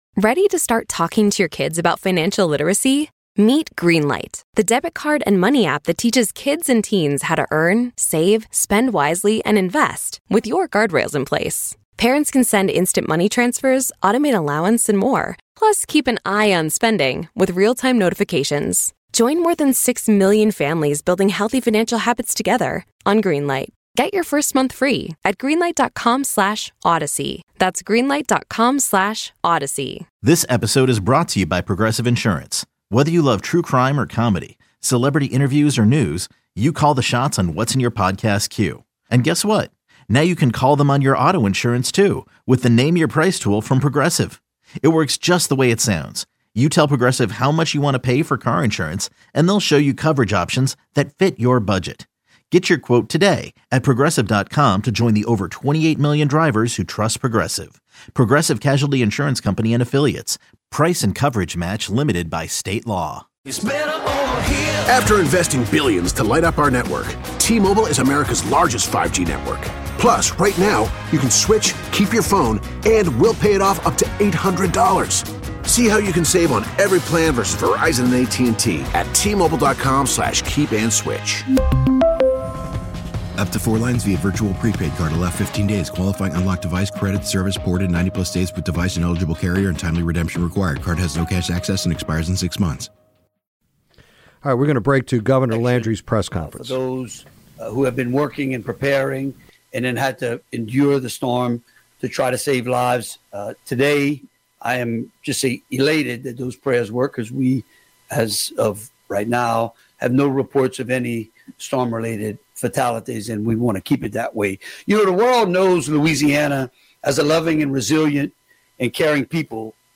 Gov. Landry Press Conference Update 9-12-24